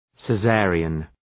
Προφορά
{sı’zerıən}